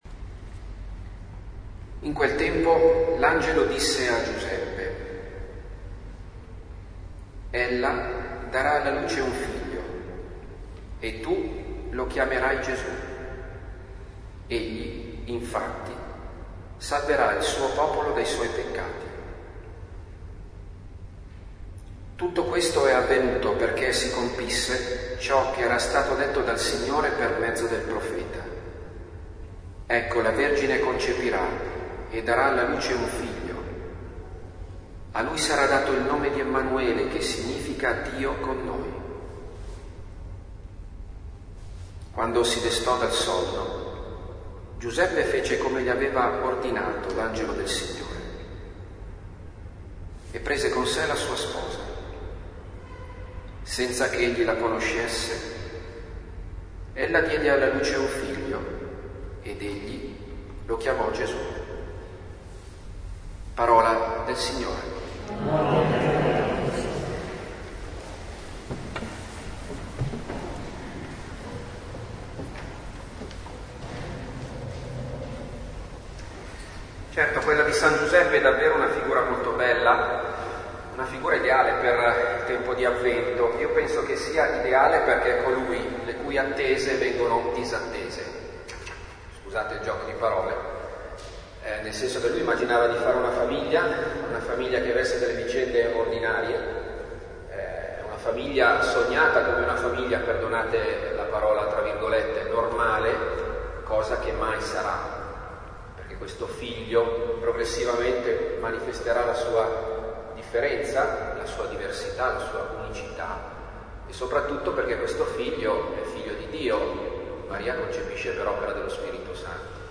Lectio divina di avvento.